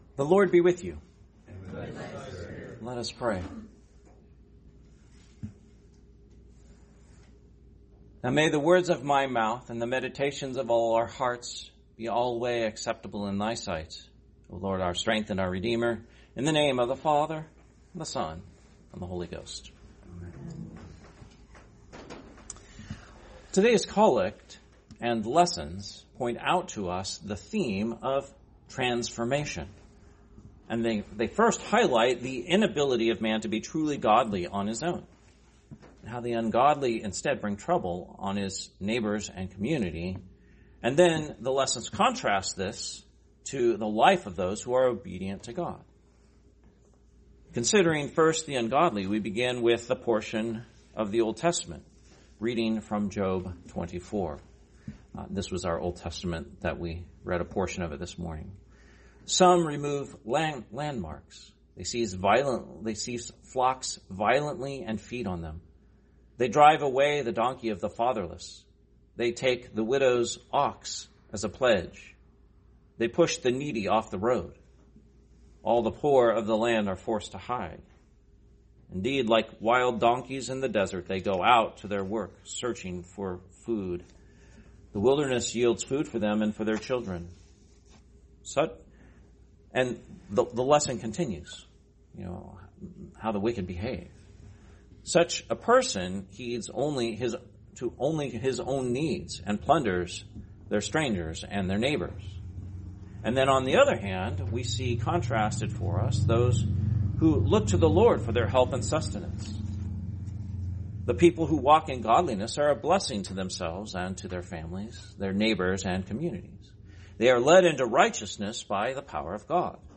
Sermon, 19th Sunday after Trinity, 2025